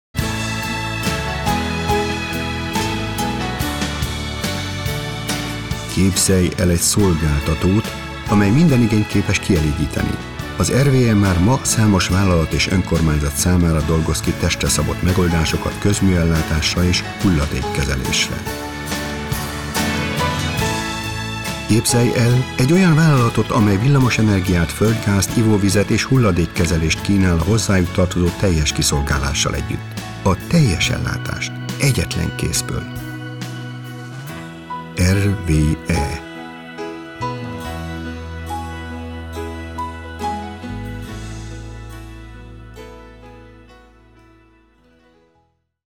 ungarischer Profi Sprecher Ausbildung: Hochschulstudium in Ungarn (Philologie) Tätigkeiten: Fremdsprachenredakteur, Sprecher, Regisseur, Moderator, Übersetzer mit journalisticher Textbearbeitung und eingetragener Dolmetscher Referenzenauszug: Allianz Versicherungen, BASF, Deutsche Welle, Ford, Hösch, KHD, Langenscheidt KG, Opel, Paul Hartmann AG, Samsung, Sony und WDR Arbeitsgebiet: Deutschland, Belgien, Niederlande, Schweiz Italien und Ungarn Produktionen: Trailer, Imagefilm, TV Film, TV Werbung, Funkwerbung, Dokumentarfilm, Lehrfilm, Hörbuch
Sprechprobe: Sonstiges (Muttersprache):
hungarian voice over artist